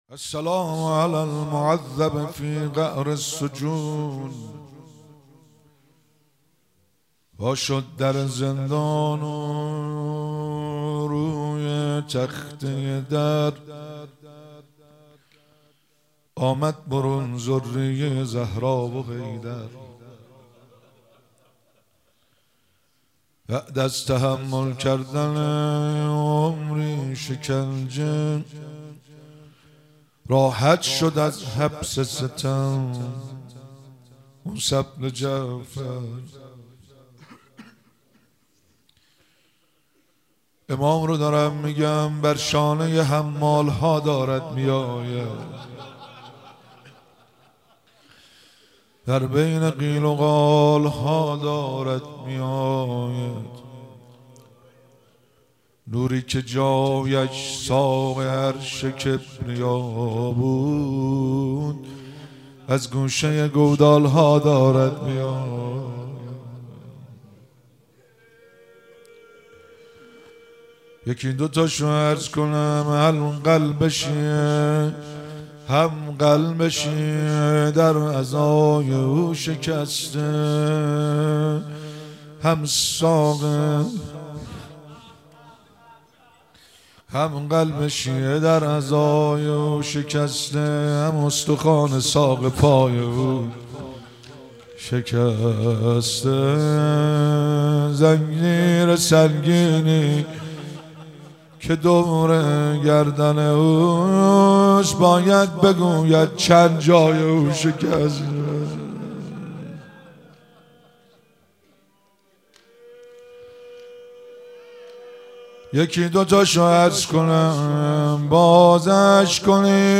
مراسم مناجات خوانی شب پنجم ماه رمضان 1444
روضه- وا شد در زندان روی تخته در